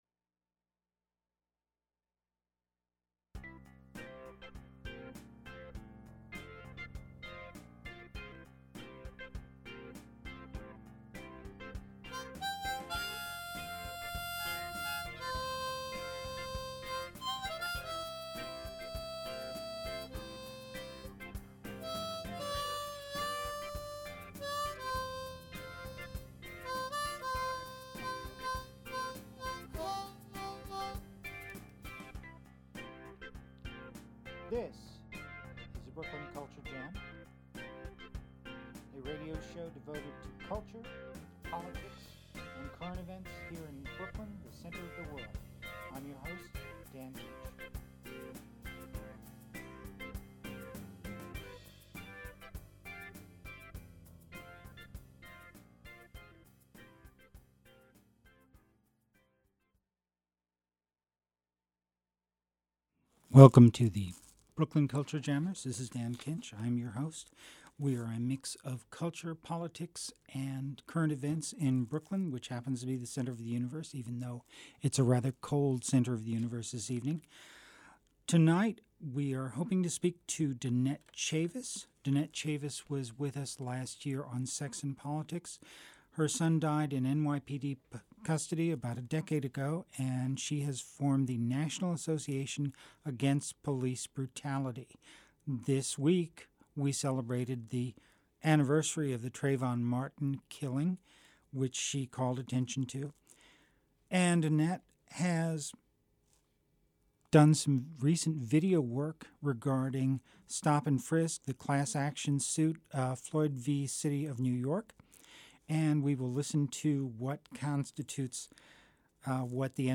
It’s called ‘the Brooklyn Culture Jam‘ and it’s styled as a mix of politics, culture, local stories and music, all coming from the borough of Brooklyn, the center of the cultural universe now that Manhattan has priced much of its arts scene out.